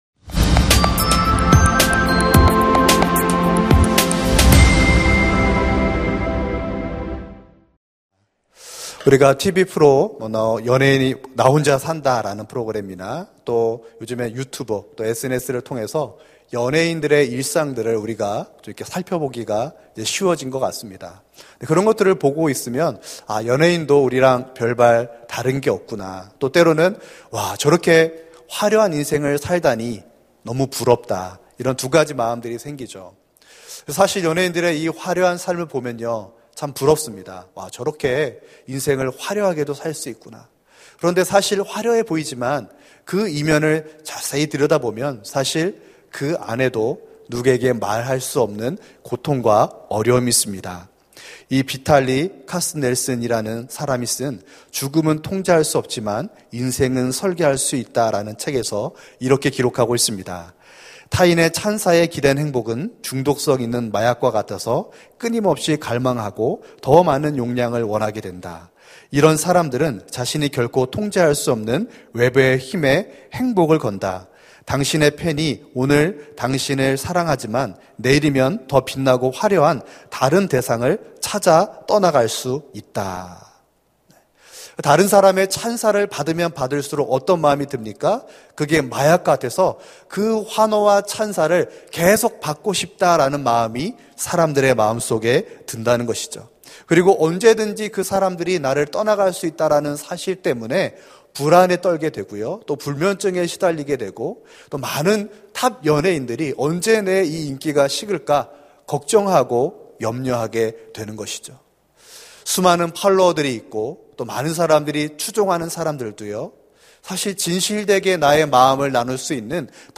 설교 : 수요향수예배 (수지채플) 어떤 길을 가겠습니까? 설교본문 : 마가복음 6:14-29